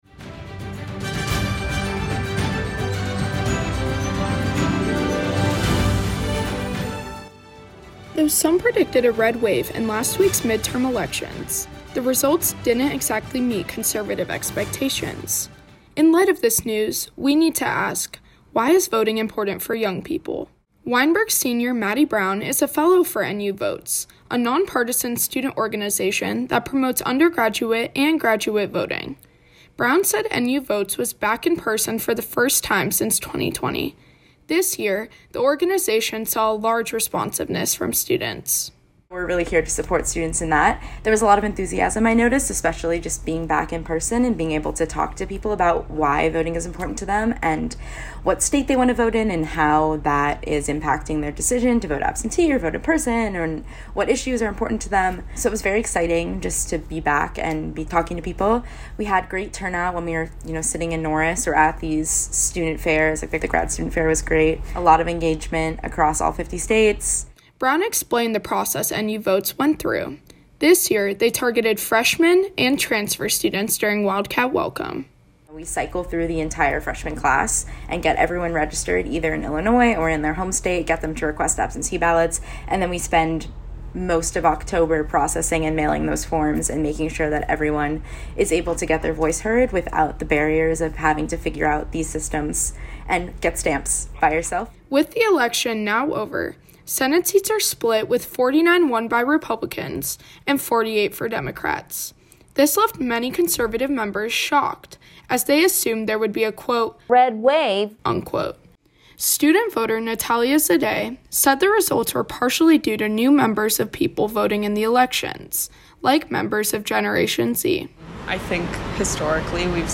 As the midterm elections came to a close last week, and the ballots rolled in some questions have risen- does your vote really count? Two students have an answer.